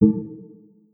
generic-select-toolbar.wav